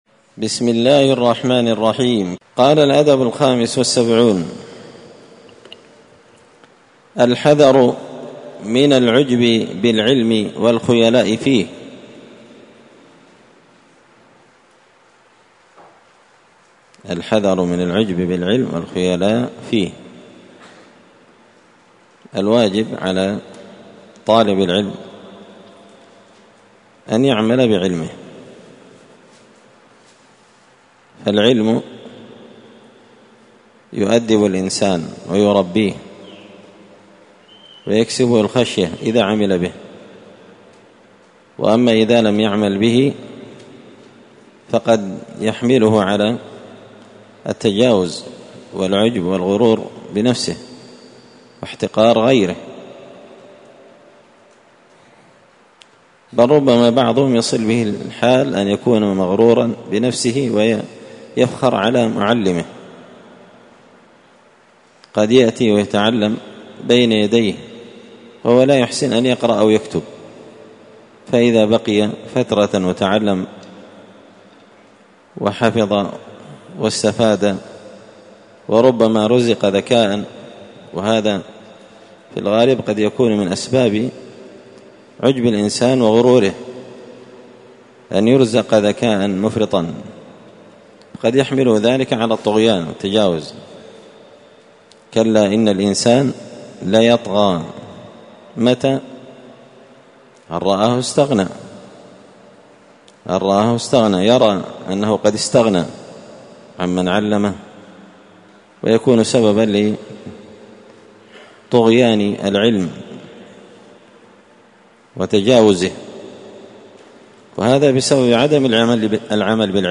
تعليق وتدريس الشيخ الفاضل:
الدرس الخامس والثمانون (85) الأدب الخامس والسبعون الحذر من العجب بالعلم والخيلاء فيه